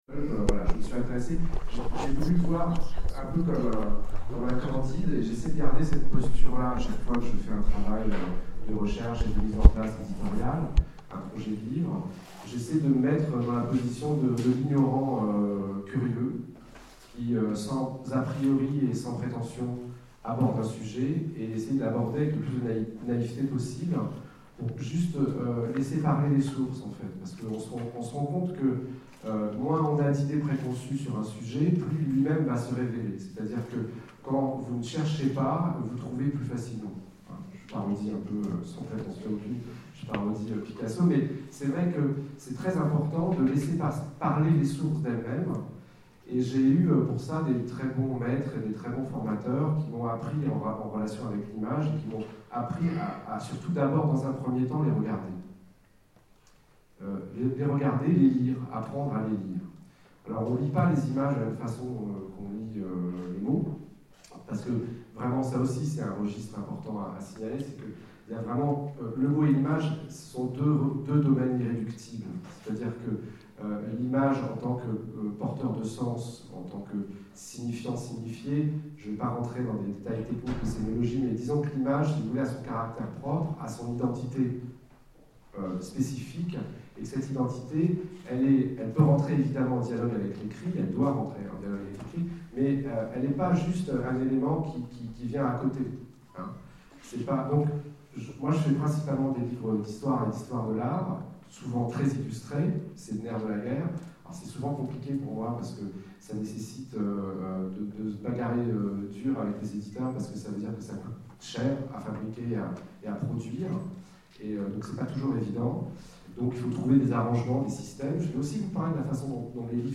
Etonnants Voyageurs 2013 : Conférence Voyages imaginaires, de Jules Verne à James Cameron